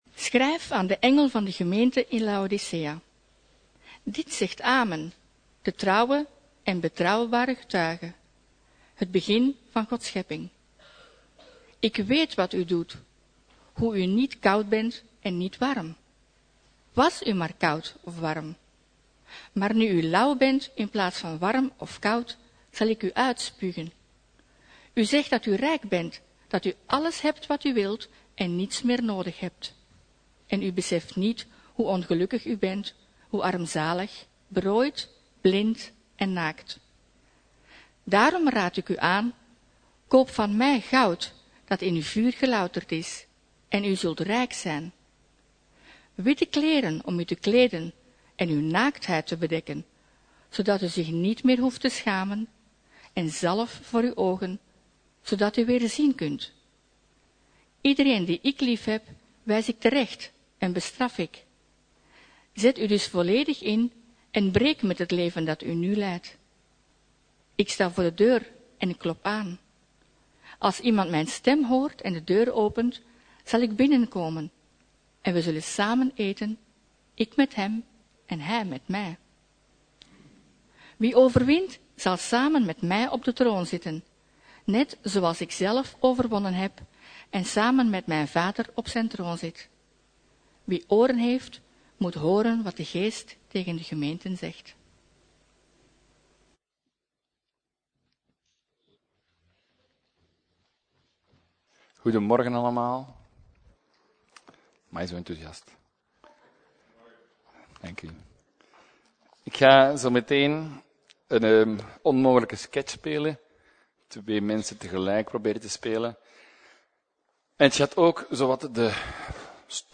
Preek: - Levende Hoop